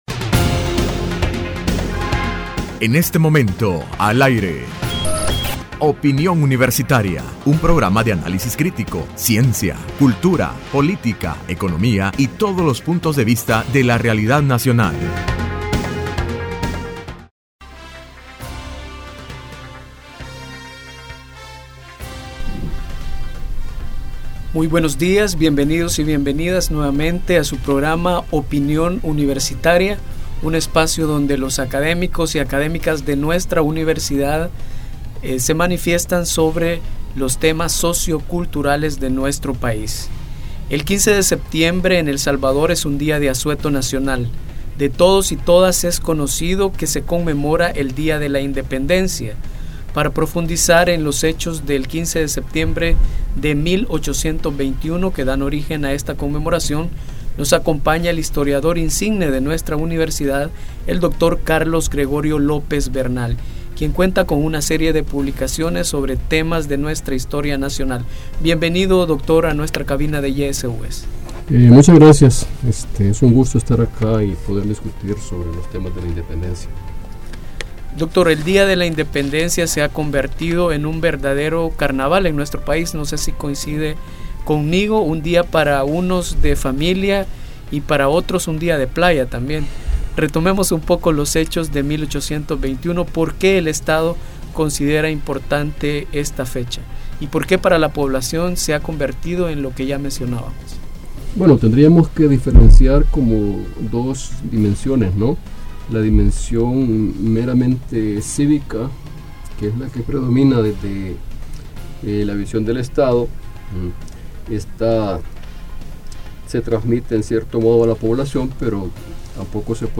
Entrevista Opinión Universitaria (12 de septiembre de 2016): Día de la independencia.